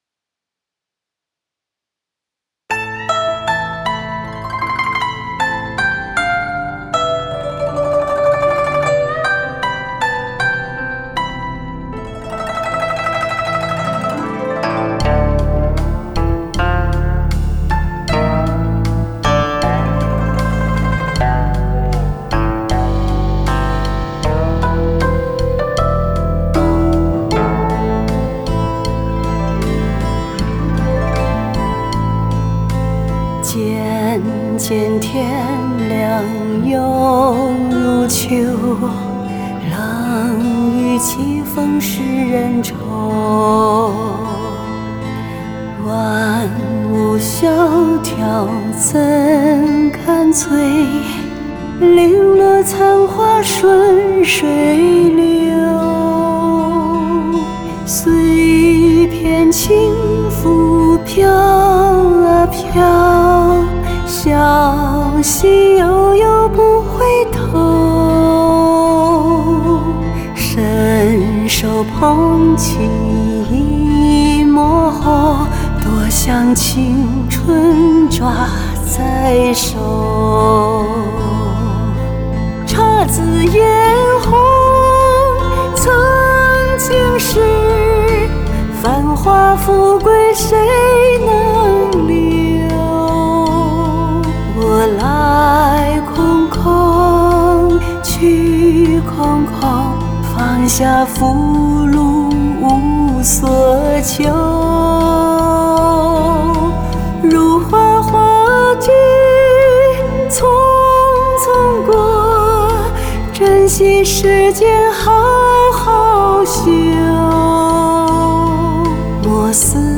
女聲獨唱：落花隨流 | 法輪大法正見網